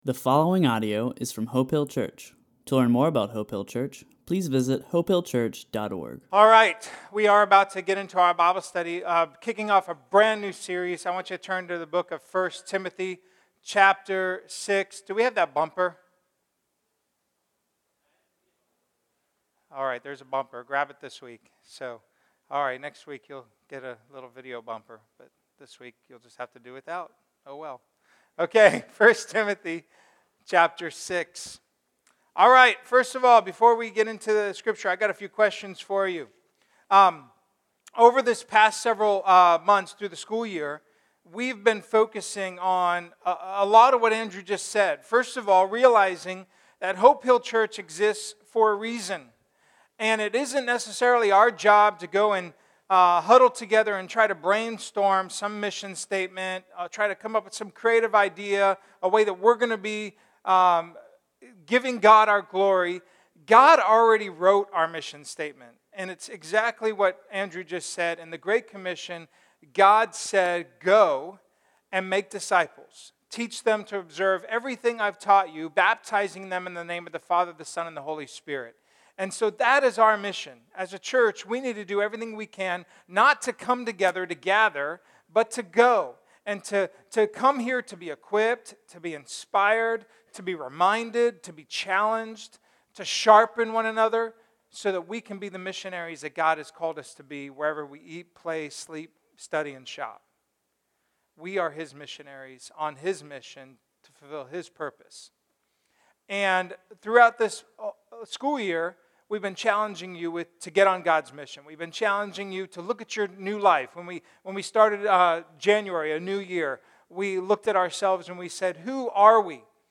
A message from the series "The Bible."